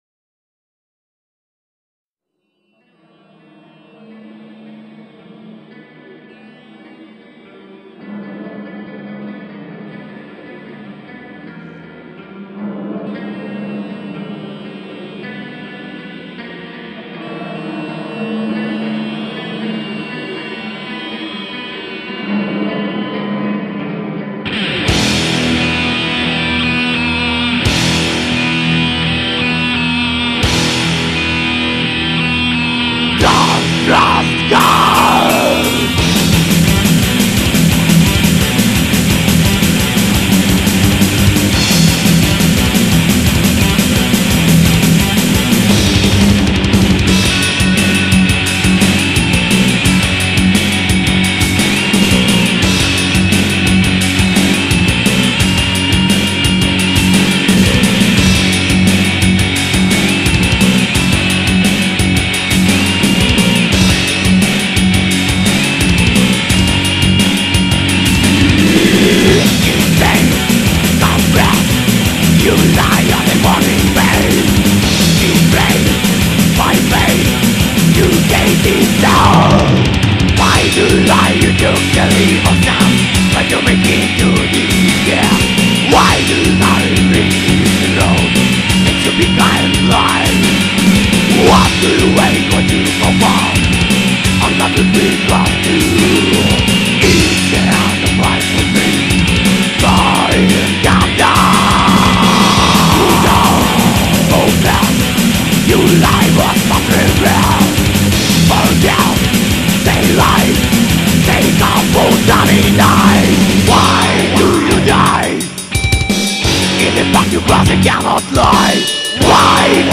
(thrash-death metal, Могилёв-Подольский)
Демо предоставленное группой :